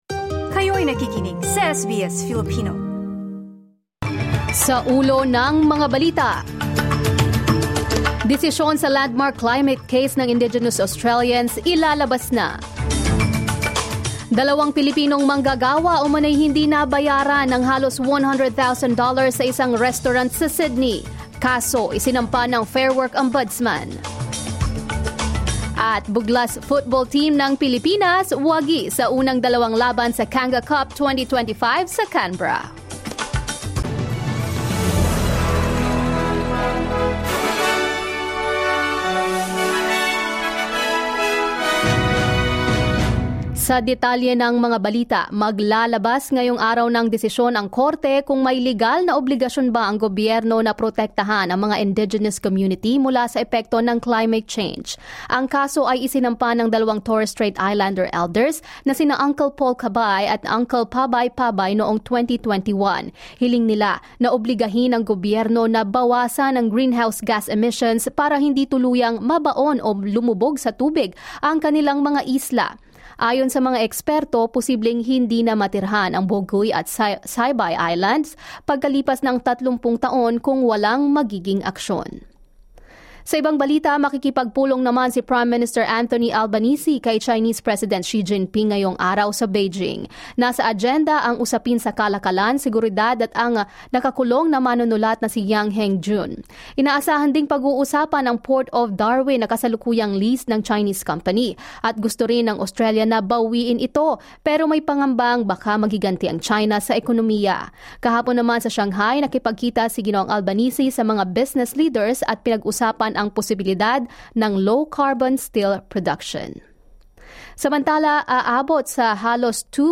Here are today's top stories on SBS Filipino.